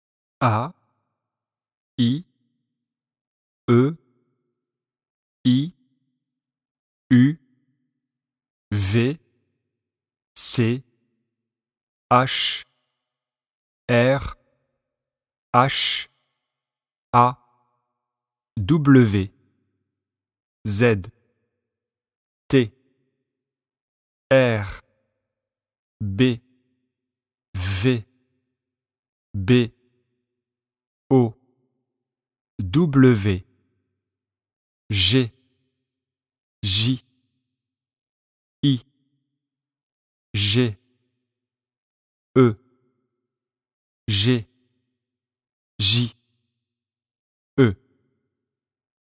🔷  Exercice 1. Lisez les lettres ci-dessous.